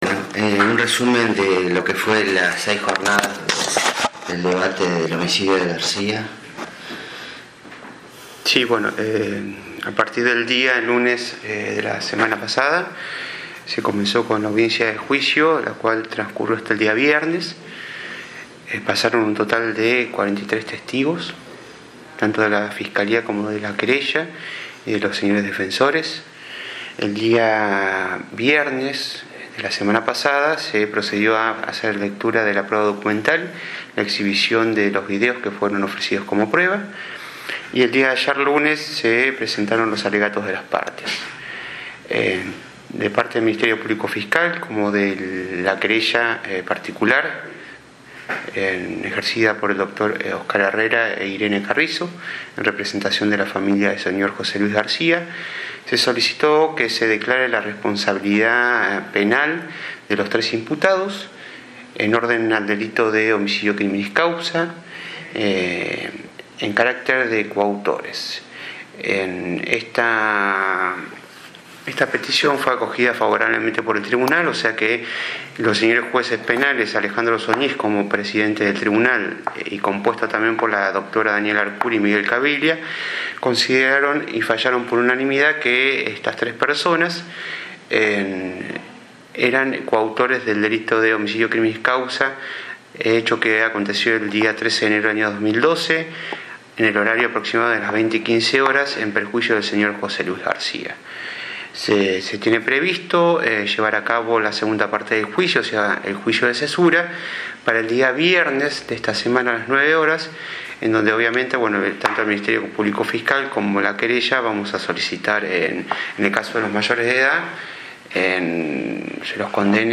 Nota (audio) al fiscal general Adrián Cabral: